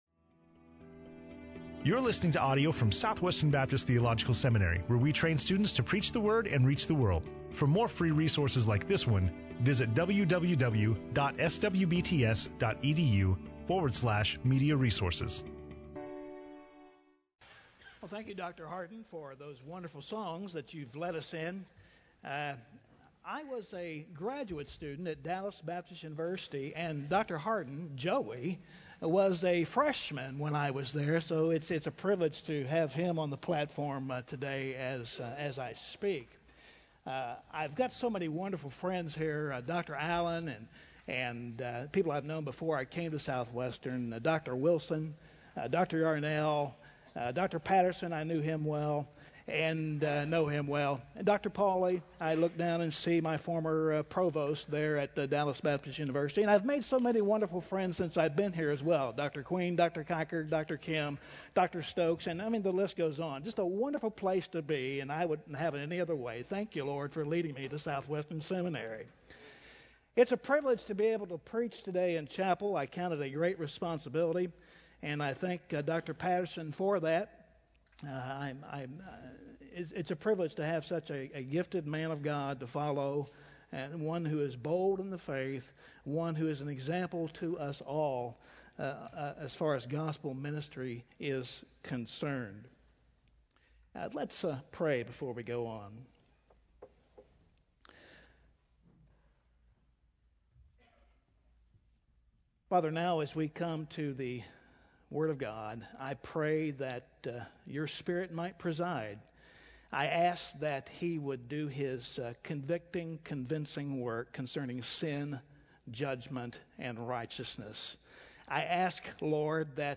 in SWBTS Chapel on Tuesday November 8, 2011